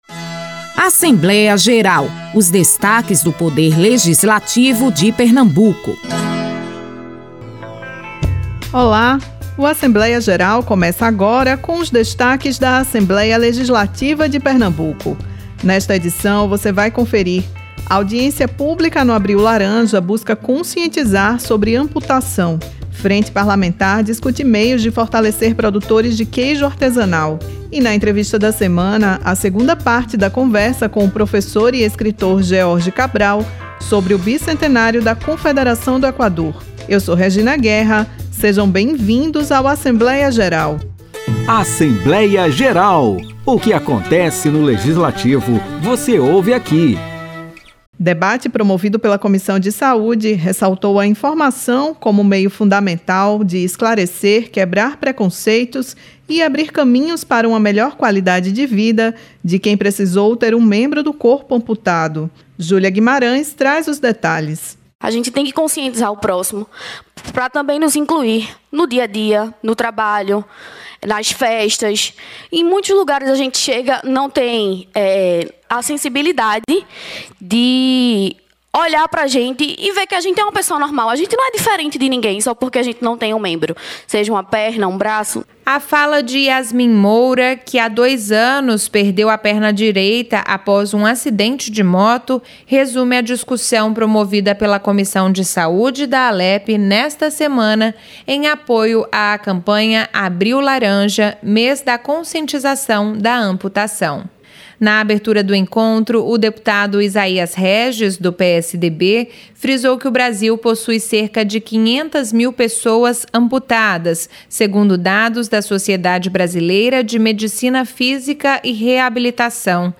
Na edição desta semana do programa Assembleia Geral, você vai conferir a audiência pública em apoio ao Abril Laranja. A ação busca conscientizar a população sobre as questões envolvendo as pessoas amputadas.